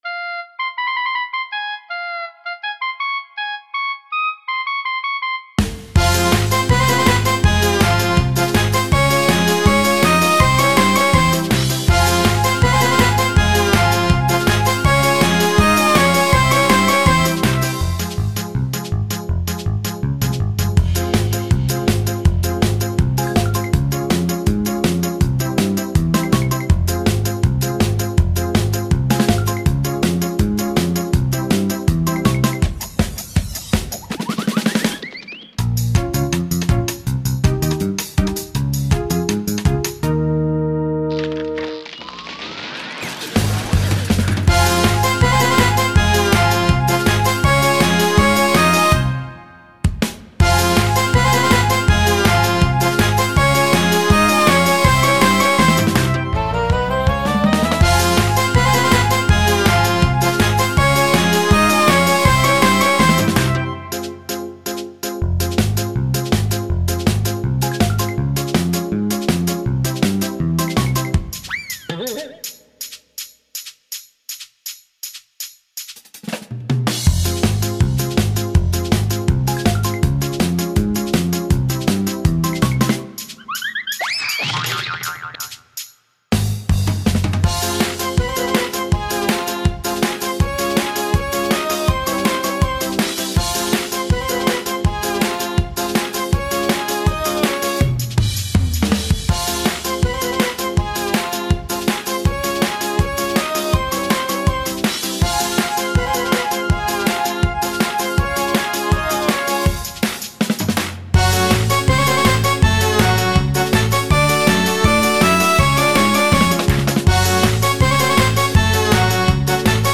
instrumental cover